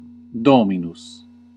Ääntäminen
US : IPA : /lɔɹd/ UK : IPA : /lɔːd/